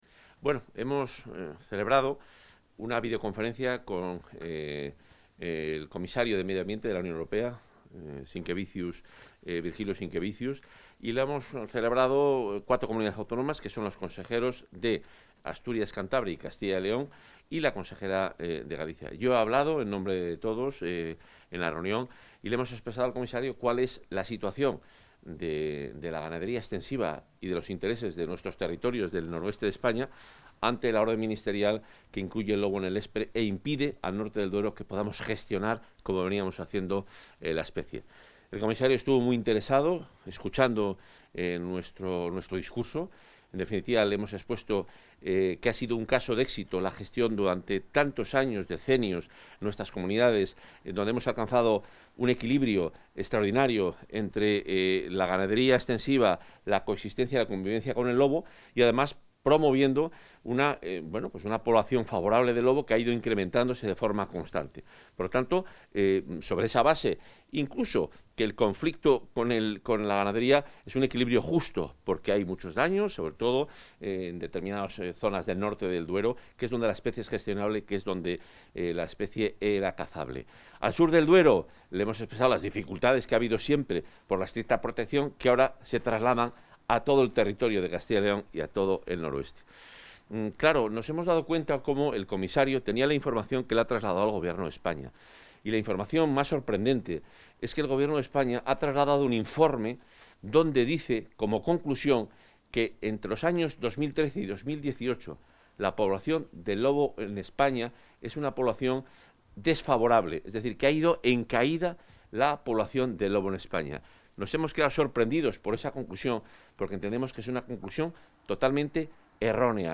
Audio consejero.